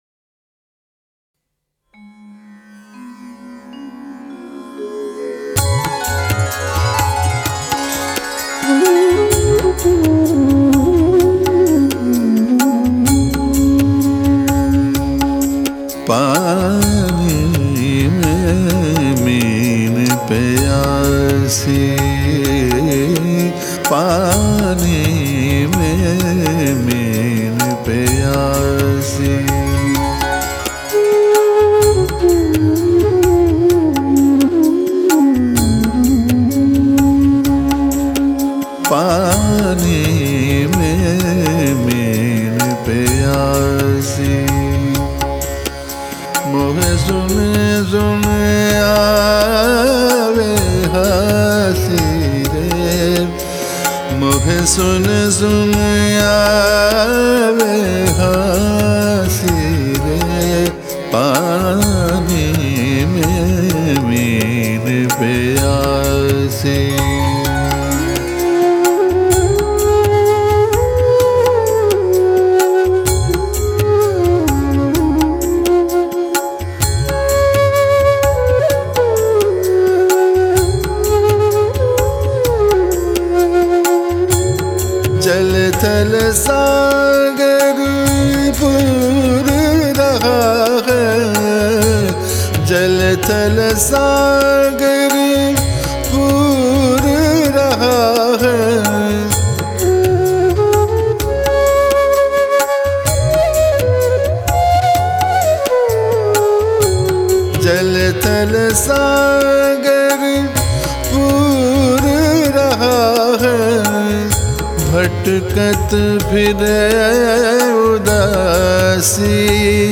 Kalam/Shabad